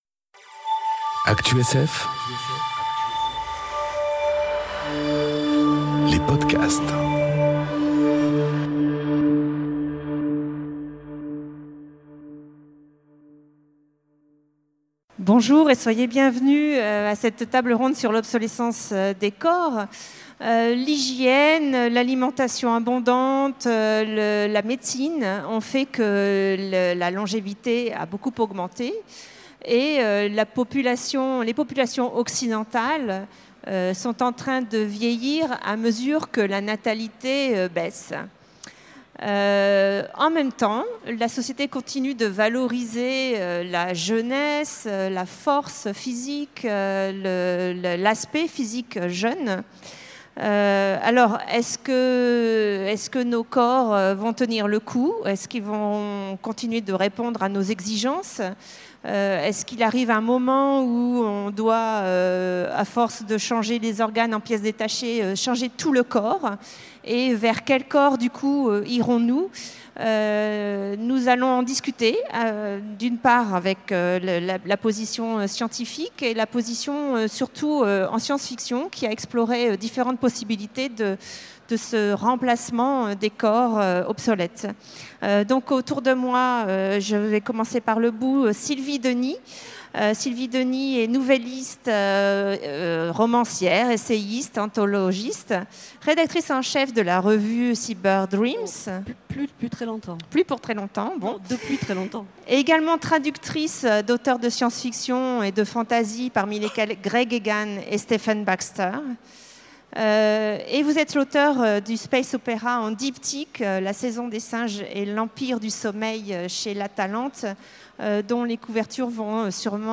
Conférence Obsolescence des corps enregistrée aux Utopiales 2018